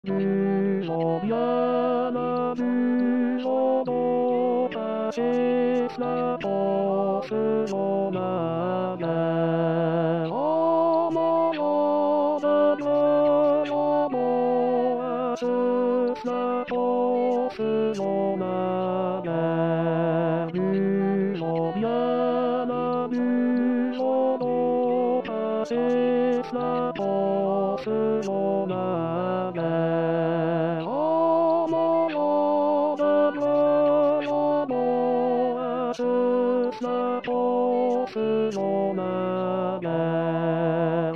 La version en yahourt du tourdion qui donnera l'occasion de tester le "plugin" pour les curieux.
Ténor
Tourdion_Tenor.mp3